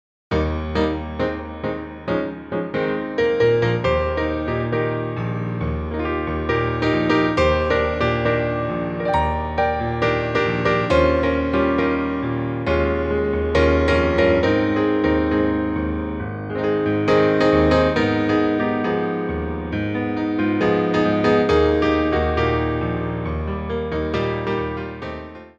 Theme Songs from Musicals for Ballet Class
Piano Arrangements
4/4 (16x8)